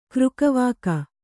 ♪ křkavāka